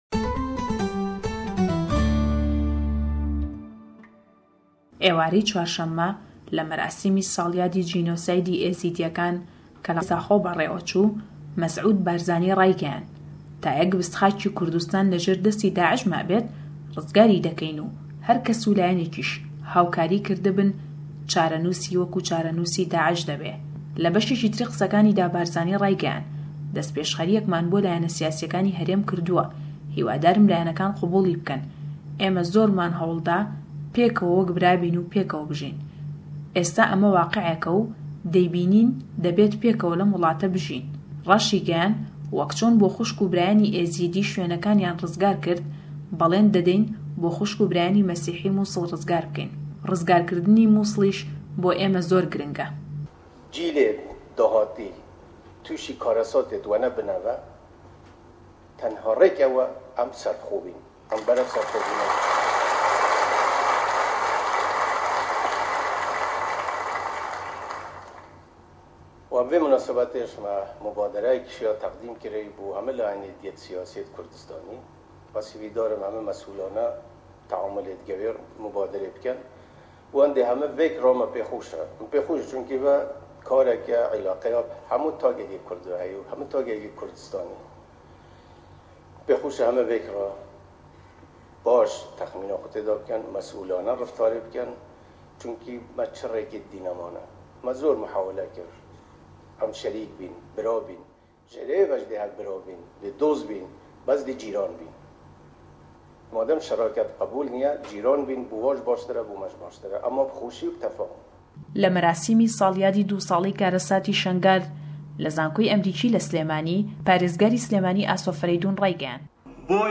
Raport